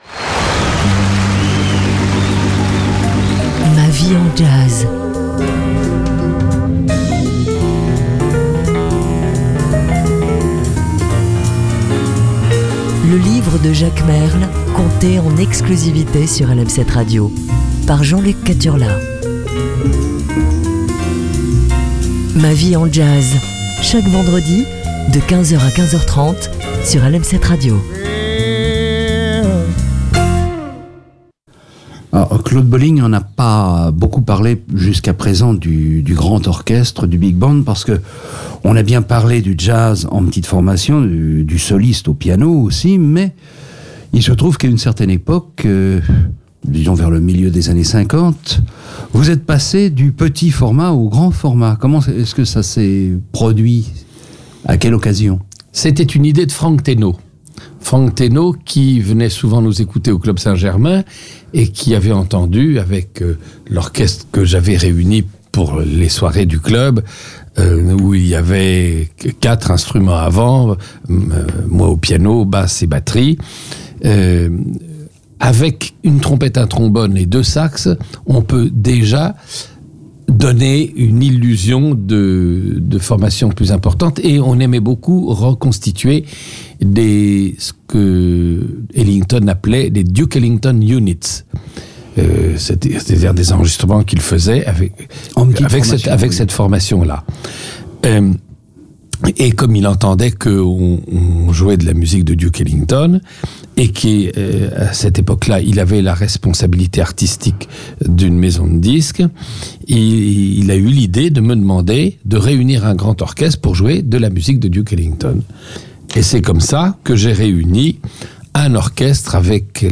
Les Vendredis sont très Jazzy sur LM7 Radio